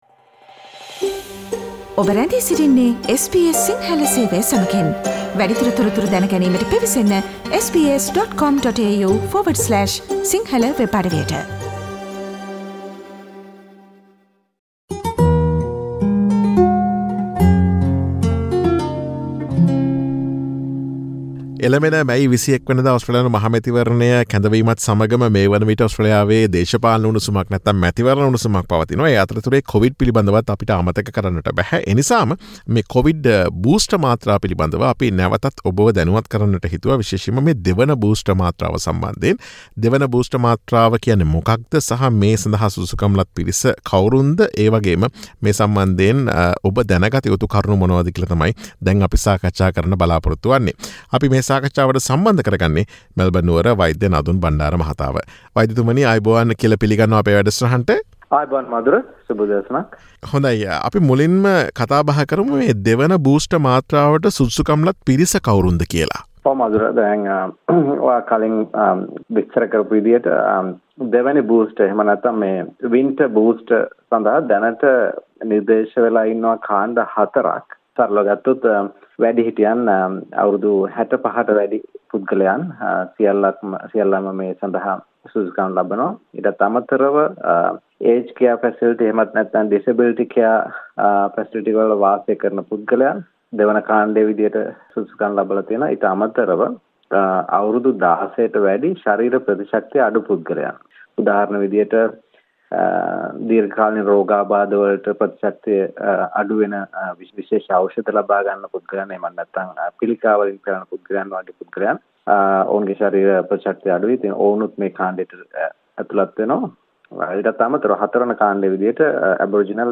Click on the speaker icon on the image above to listen to SBS Sinhala Radio's discussion on getting the second booster dose in Australia.